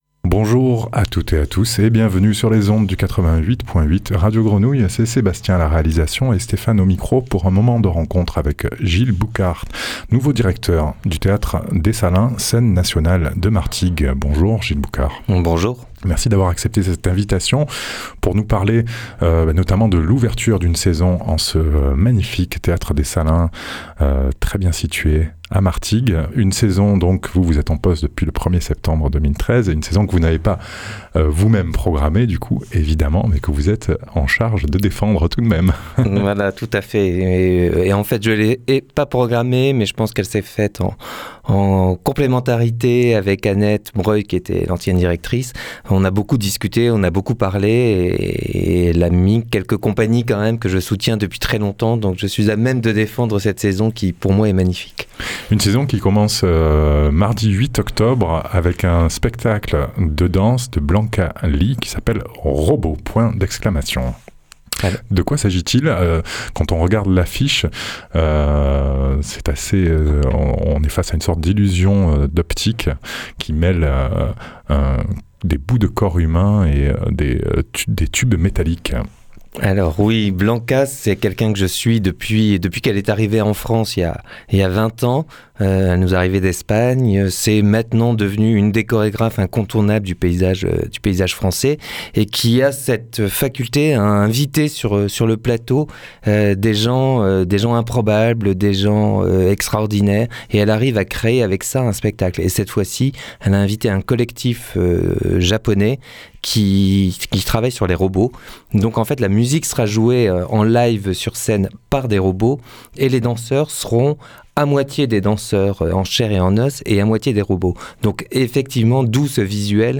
Rencontre dans le studio des 3 8 et survol du premier trimestre de programmation de la saison.